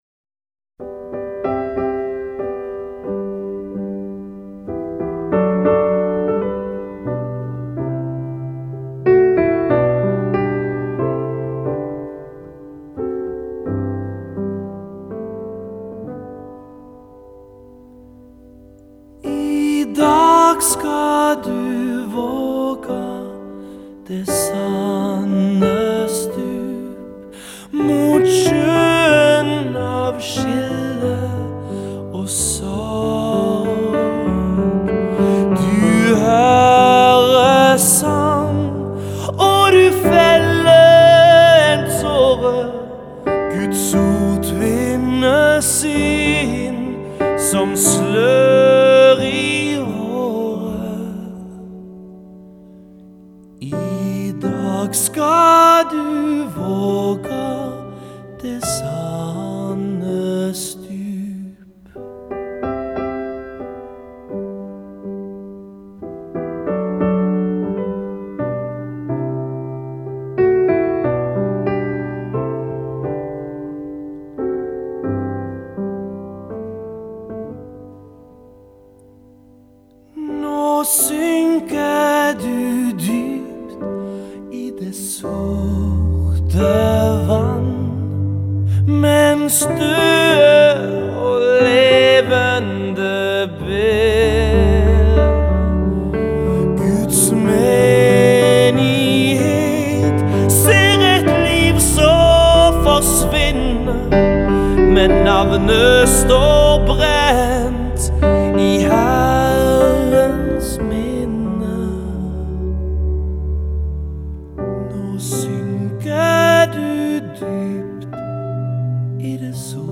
스팅의 목소리일까?